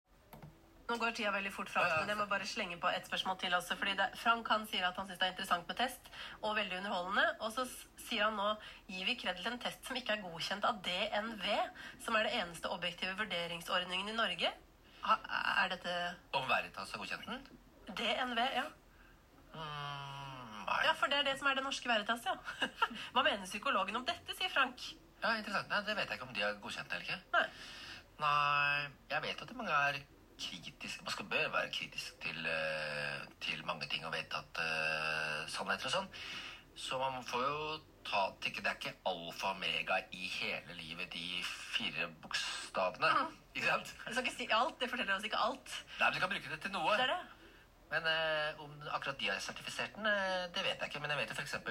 NRK intervjuet en kjent psykolog om personlighetstesting.
4. juni viet NRK en time til å snakke om personlighetstester sammen med en kjent psykolog.